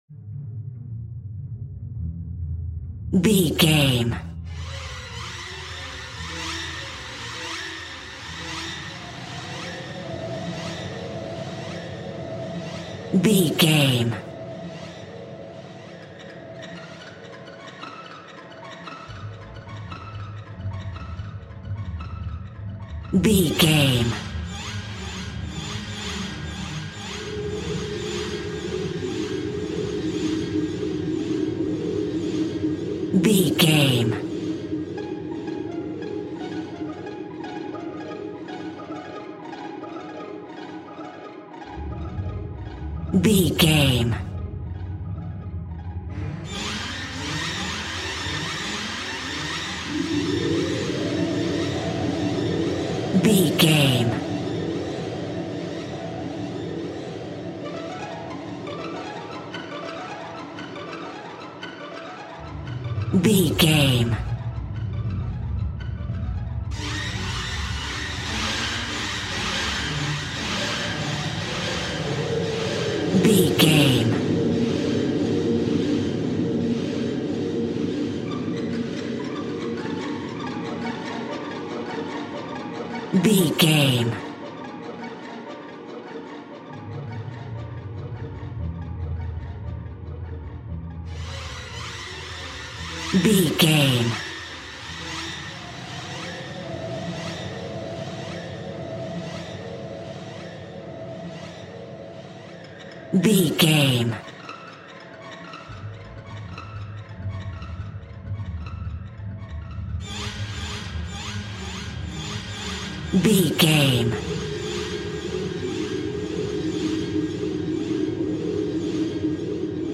Aeolian/Minor
ominous
eerie
synthesizer
Horror Ambience
Synth Pads